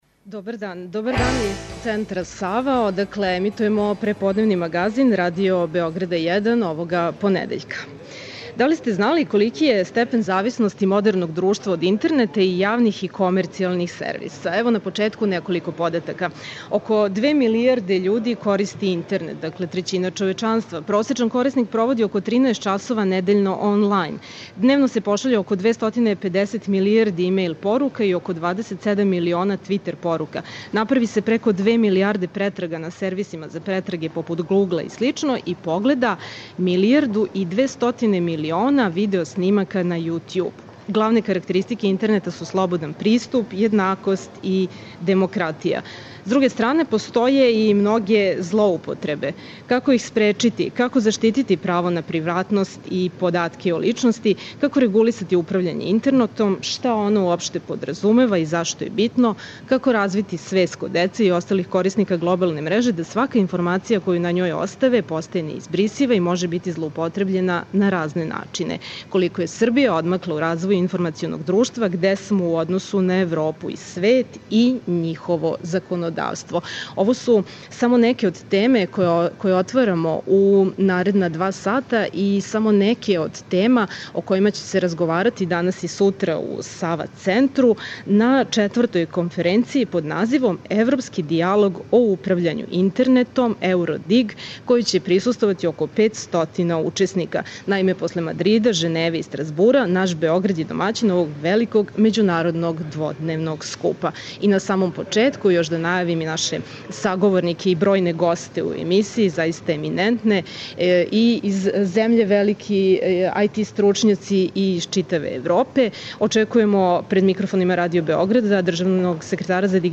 Колико је Србија одмакла у развоју информационог друштва, где смо у односу на Европу и свет и њихово законодавство? Ова и многа друга питања (дигитална дипломатија, образовање и Интернет, грађански активизам на глобалној мрежи) биће отворена у Магазину, који овога понедељка емитујемо из Центра "Сава", са Међународне конференције посвећене управљању Интернетом – ЕУРОДИГ.